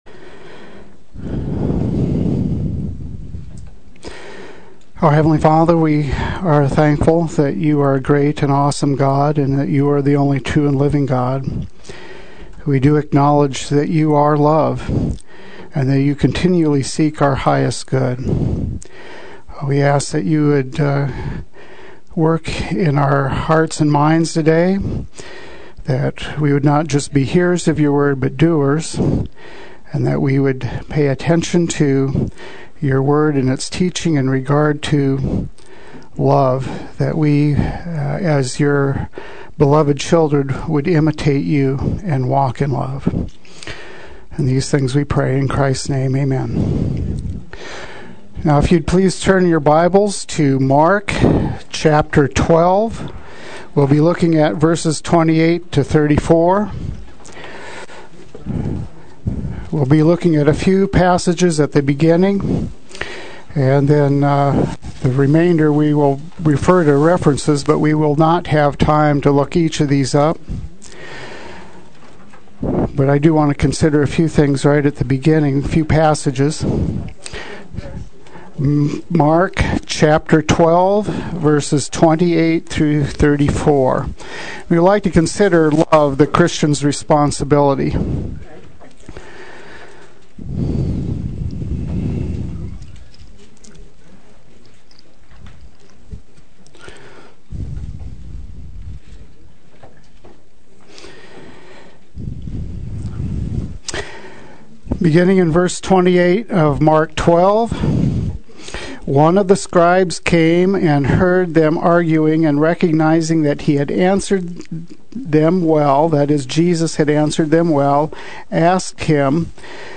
Play Sermon Get HCF Teaching Automatically.
The Christian’s Responsibility Adult Sunday School